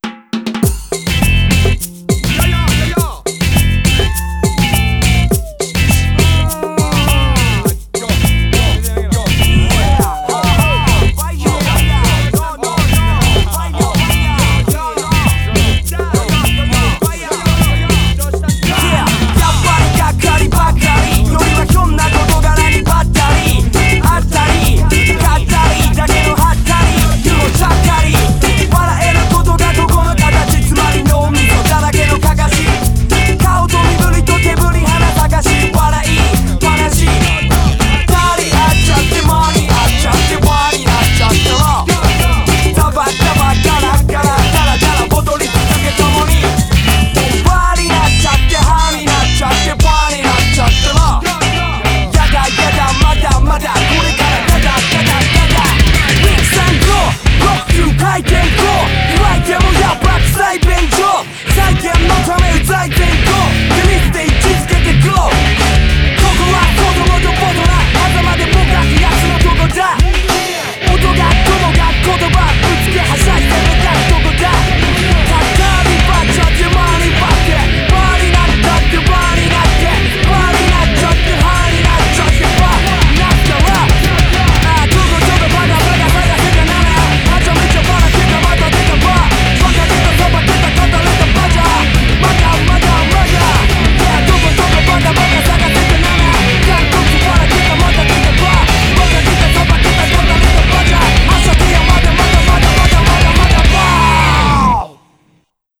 BPM102-205
Audio QualityPerfect (High Quality)
100% Des-REGGAE!